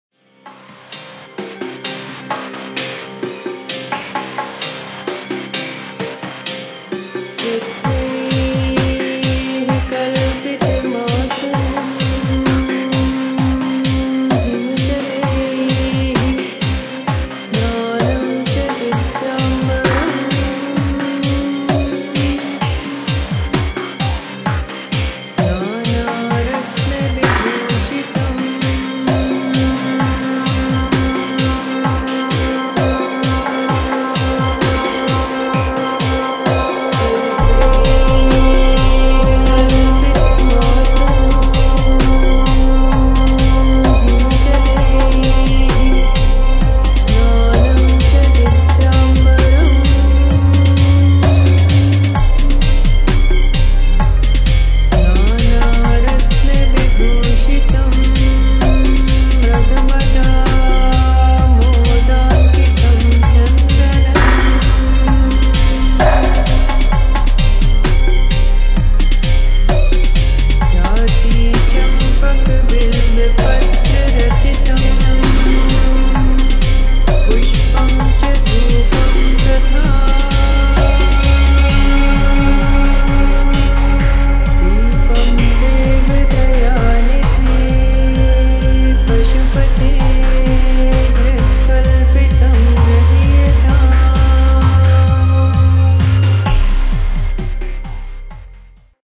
[ DUBSTEP / DUB ]